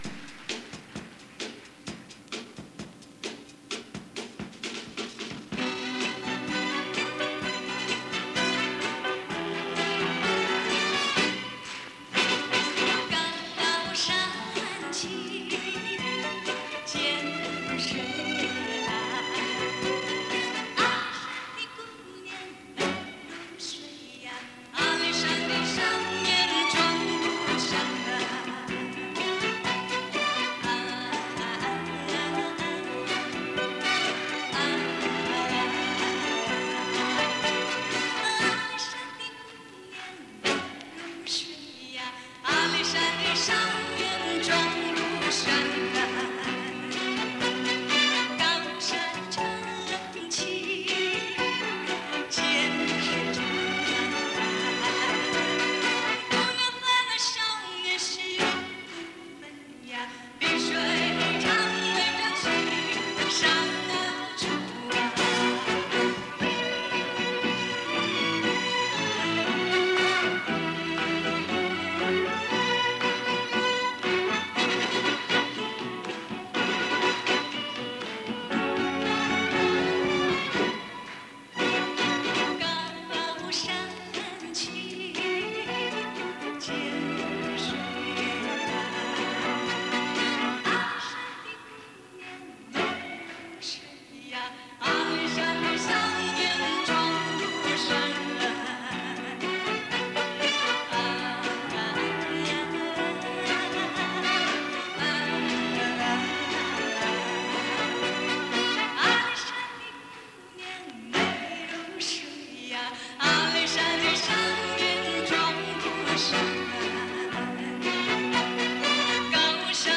磁带数字化：2022-08-19